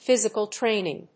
アクセントphýsical tráining